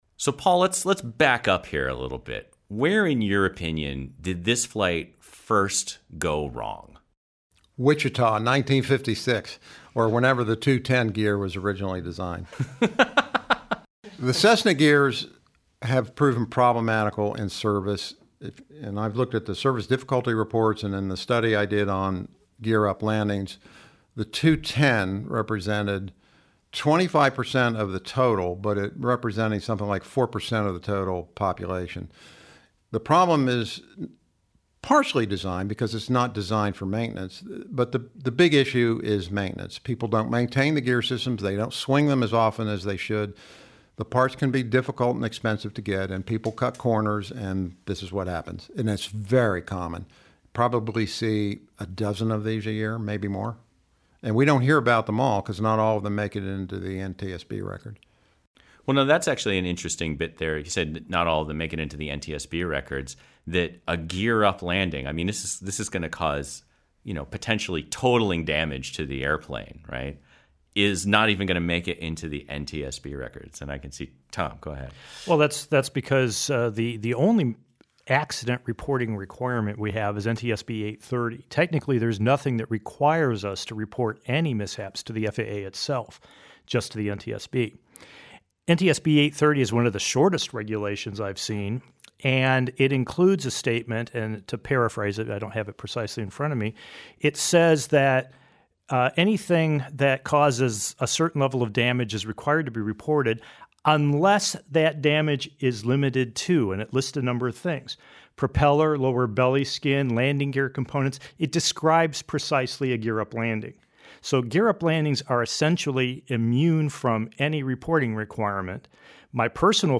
Listen in as our team of instructors discuss and debate the details of this scenario.